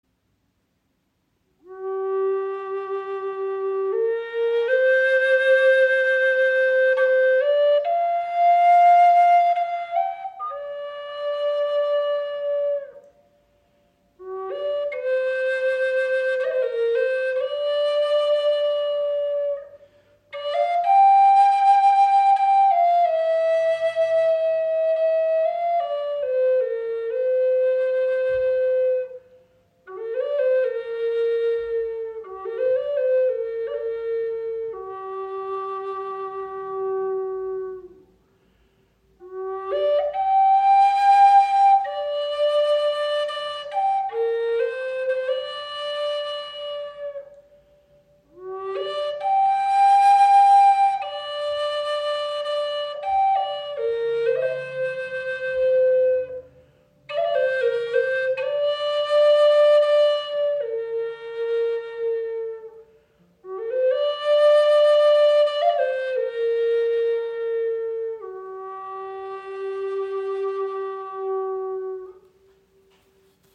Gebetsflöte in G - 440Hz
Mit 58 cm Länge liegt sie angenehm in der Hand und entfaltet tiefe, resonante Töne.
Der Flötenkörper besteht aus blau gefärbtem Curly Ahorn, dessen lebendige Maserung nicht nur ästhetisch beeindruckt, sondern auch den warmen, klaren und resonanten Klang unterstützt.
Mit ihrer Länge von 58 cm entfaltet sie tiefe, volle Töne, die sowohl in der Meditation als auch beim Solospiel den Raum erfüllen.